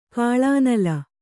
♪ kāḷānala